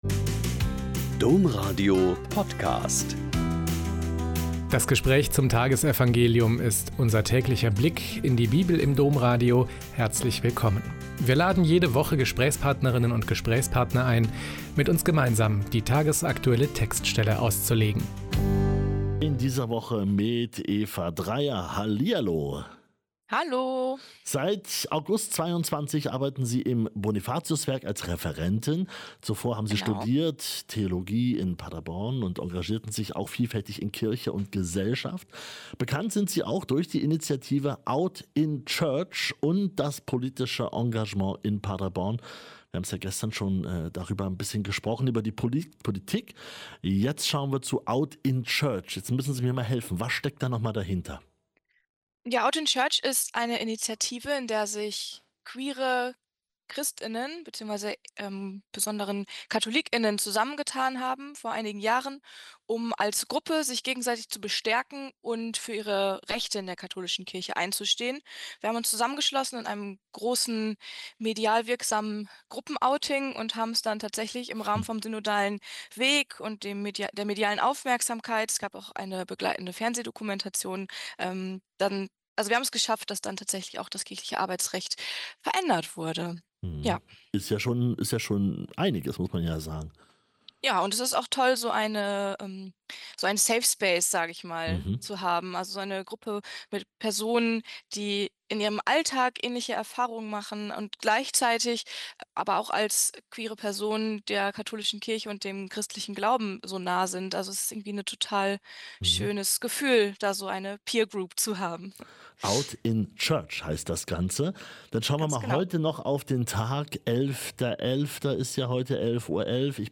Lk 17,7-10 - Gespräch